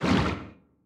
Sfx_creature_bruteshark_swim_fast_07.ogg